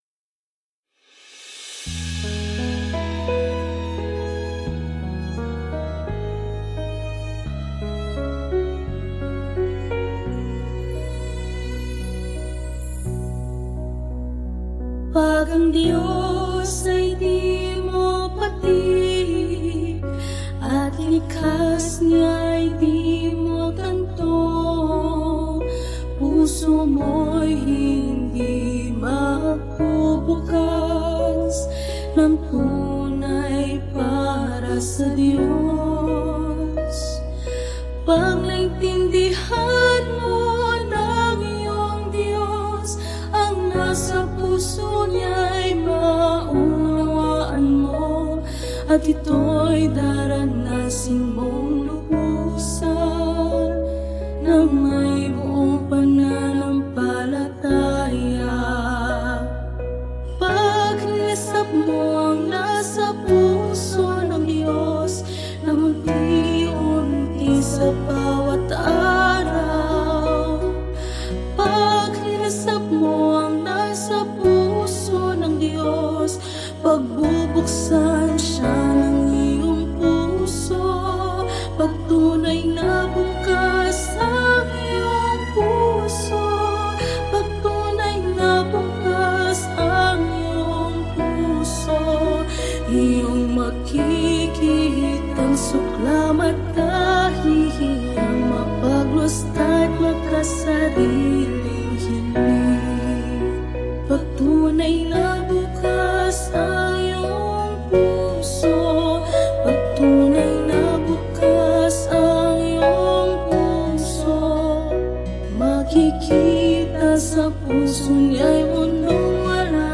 Categories: Hymns of God's Words